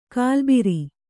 ♪ kālbiri